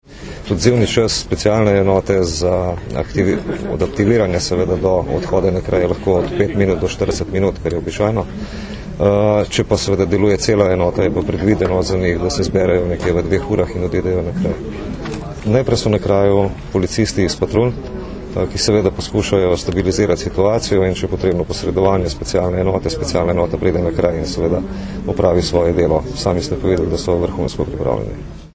Izjava namestnika generalnega direktorja policije Matjaža Šinkovca (mp3)